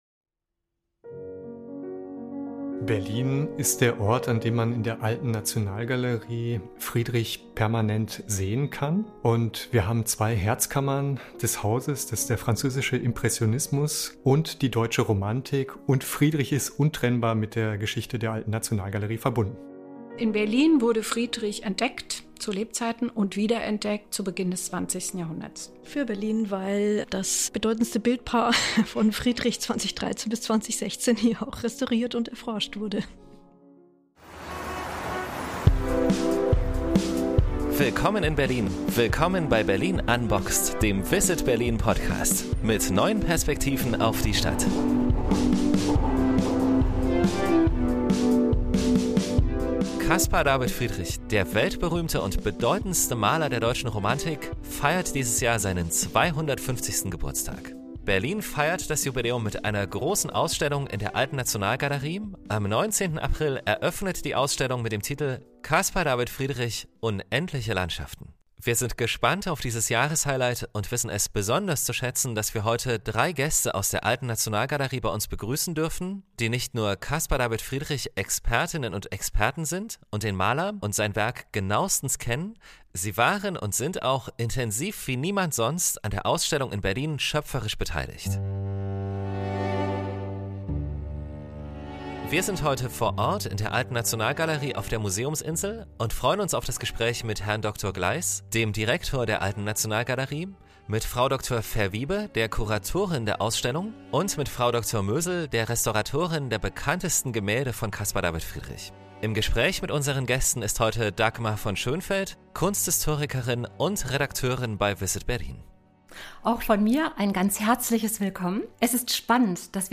In dieser Podcast-Episode sprechen wir mit Caspar David Friedrich-Expertinnen und Experten, die den Maler und sein Werk genauestens kennen und seit Jahren erforschen.